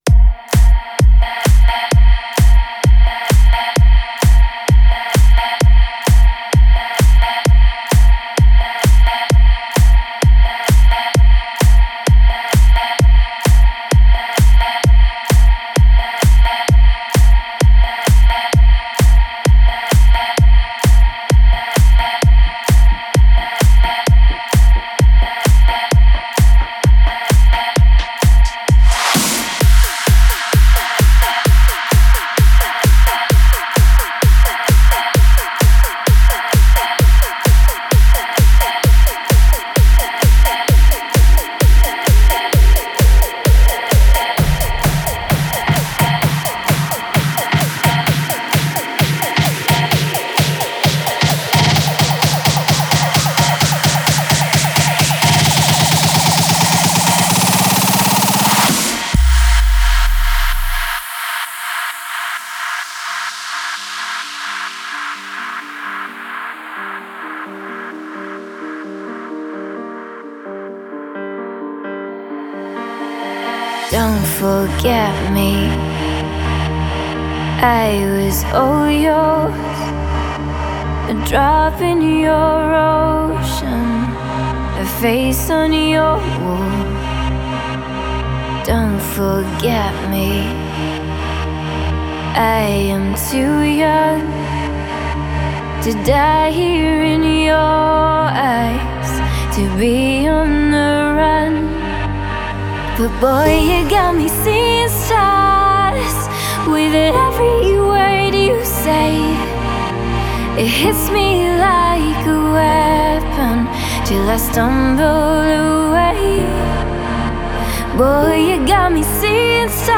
Стиль: Progressive Trance / Vocal Trance